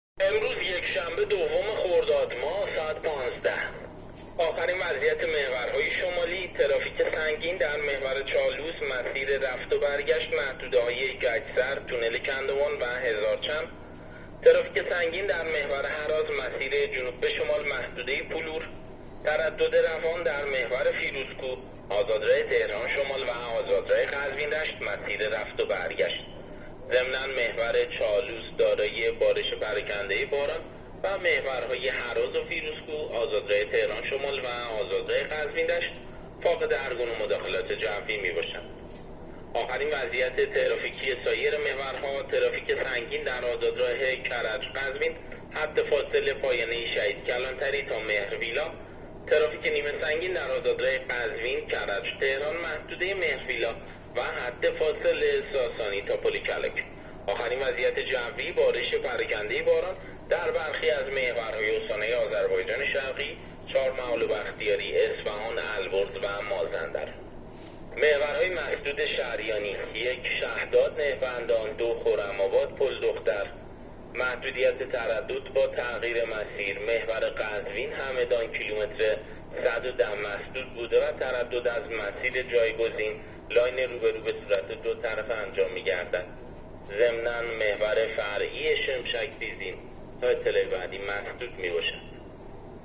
گزارش رادیو اینترنتی از آخرین وضعیت ترافیکی جاده‌ها تا ساعت ۱۵ دوم خرداد